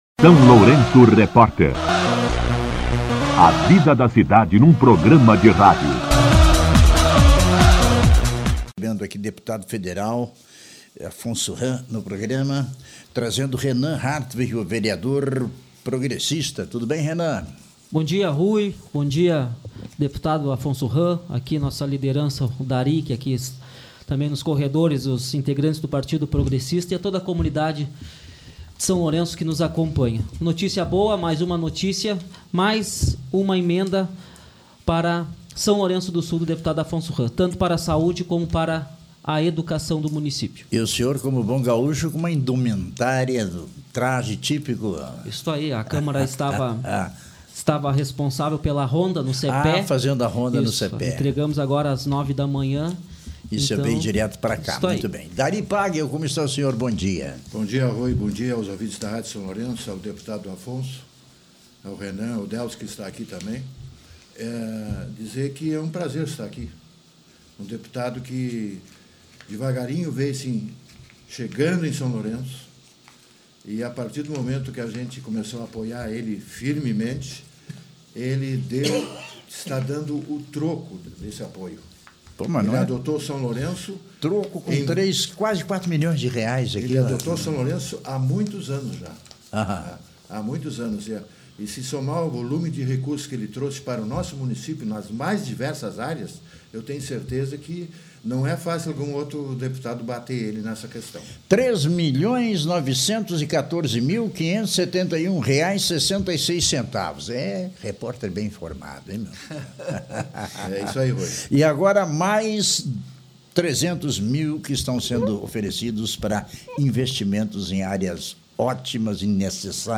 Entrevista com O deputado federal Afonso Hamm, vereador Renan Hartwig e o ex-prefeito Dari Pagel
Em entrevista ao SLR Rádio, o parlamentar destacou os recursos destinados ao município por meio de emendas parlamentares.